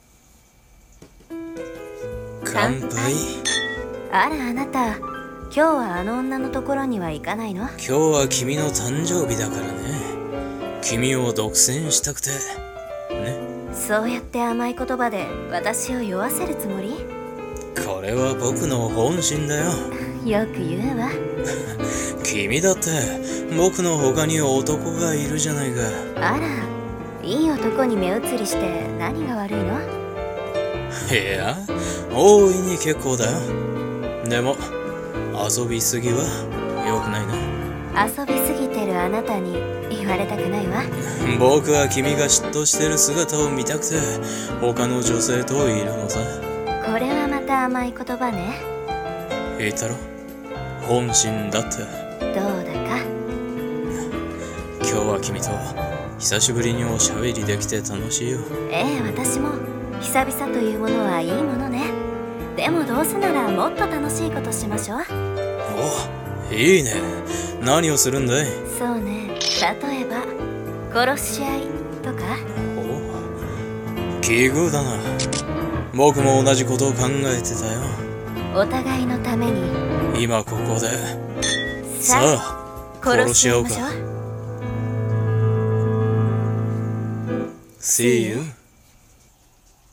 【声劇】ー密談ー(コラボ)